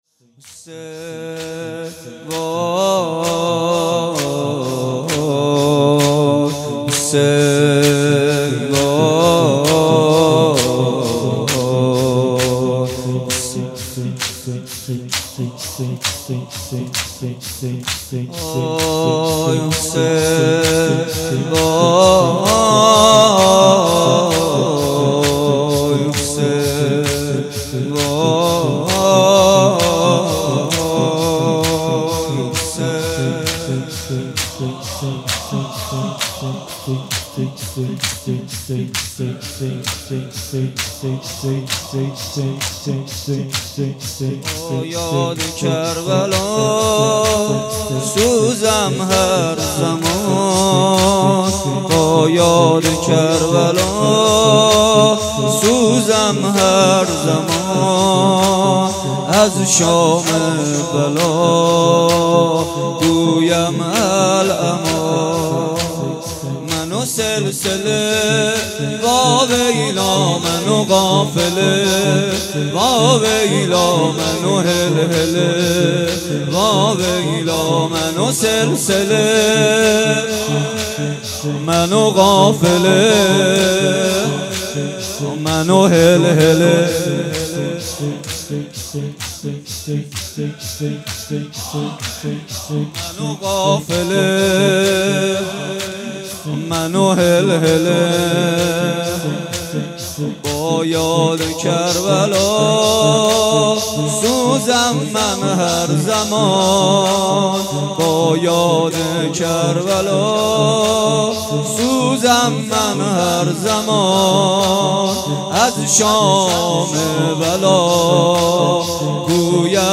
مداحی شهادت امام سجاد محمدحسین پویانفر | یک نت